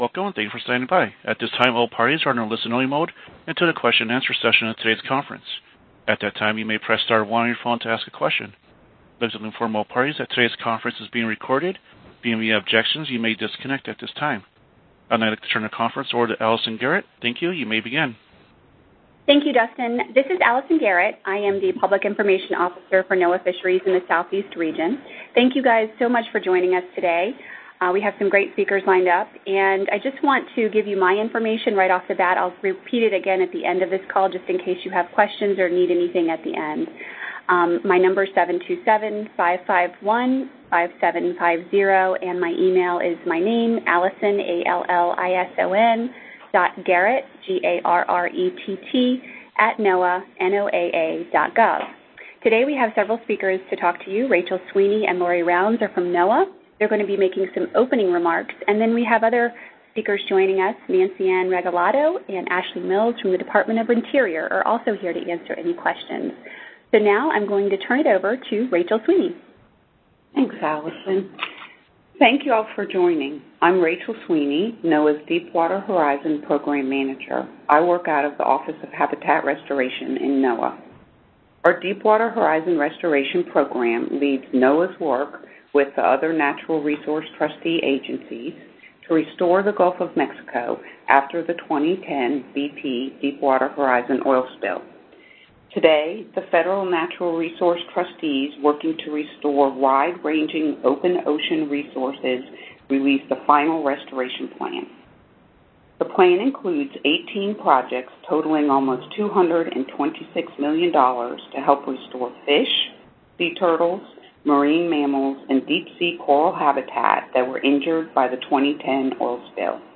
Today, the Deepwater Horizon Natural Resources Trustees released a restoration plan for the Open Ocean Restoration Area. A media teleconference is scheduled this afternoon at 1:00 pm ET.